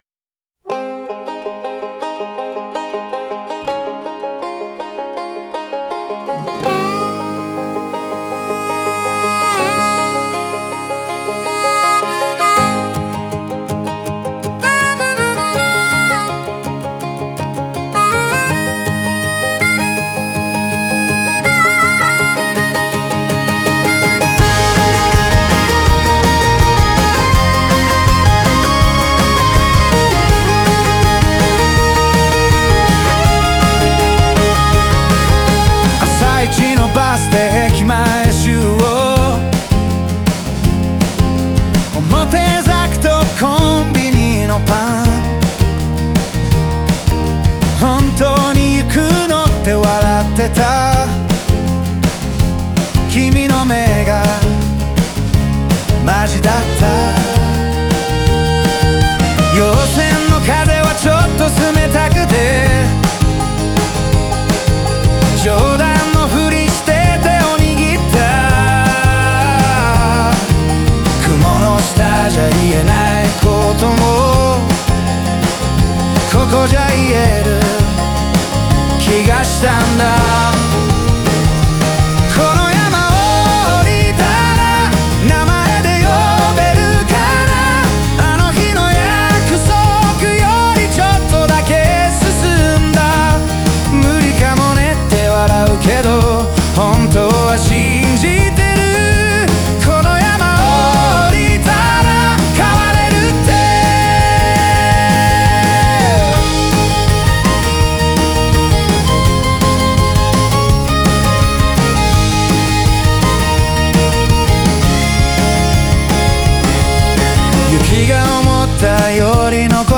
フォークロック調の軽快なリズムが、青春の不器用でまっすぐな感情を彩っている。